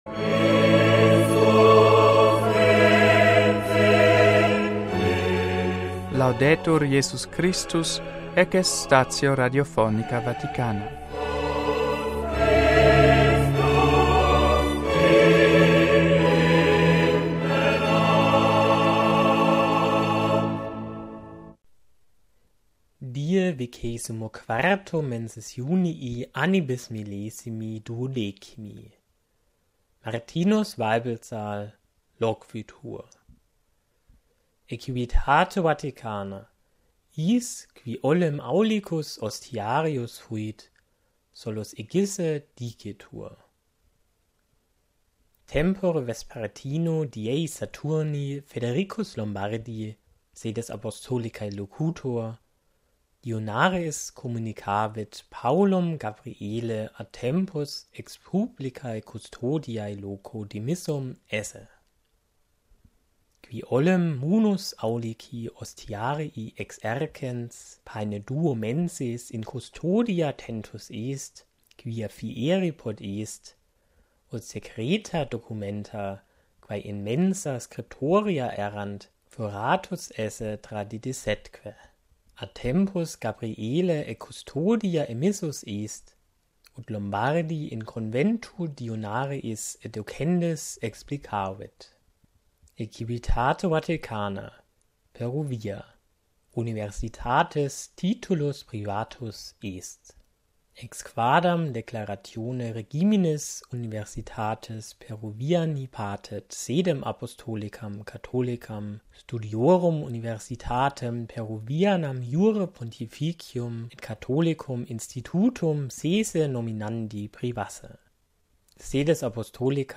NUNTII STATIONIS RADIOPHONICAE VATICANAE PARTITIONIS GERMANICAE IN LINGUAM LATINAM VERSI